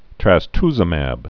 (trăs-tzə-măb)